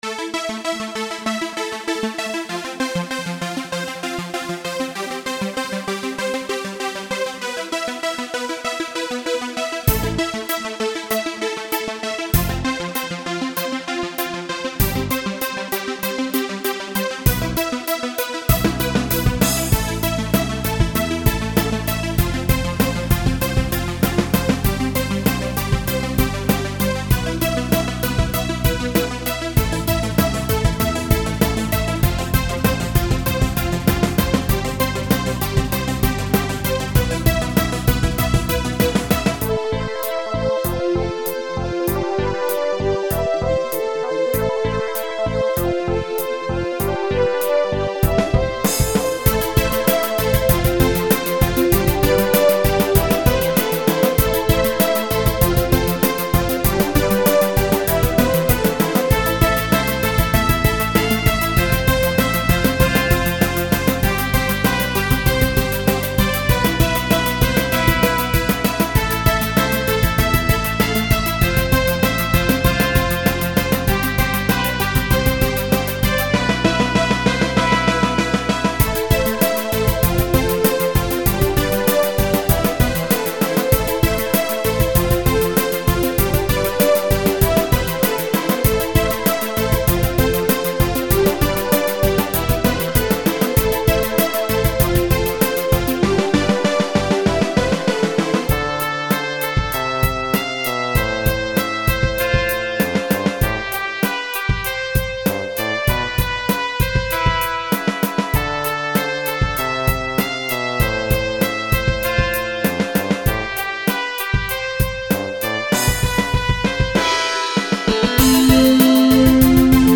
Мексиканский народный танец